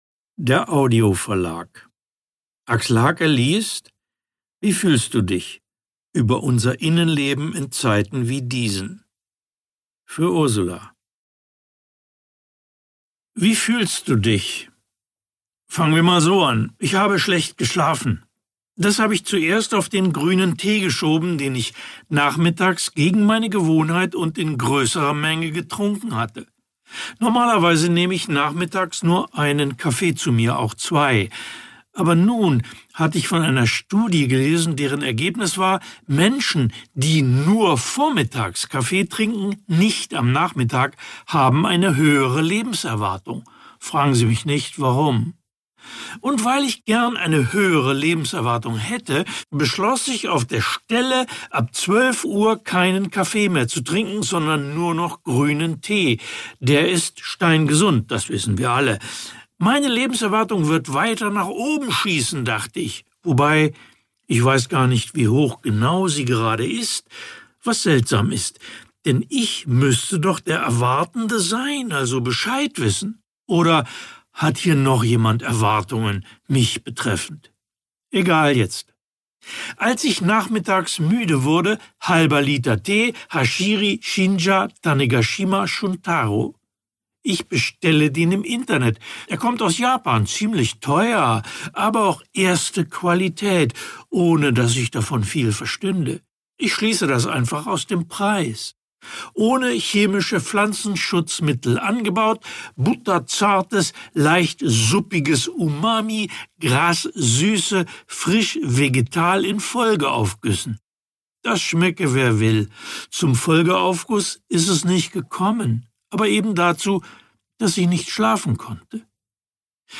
Axel Hacke (Sprecher)
Ungekürzte Autorenlesung mit Axel Hacke